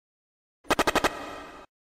Pokemon RBY Poison Sound
pokemon-redblueyellow-poison-sound-effect.mp3